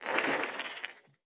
Ice Skate D.wav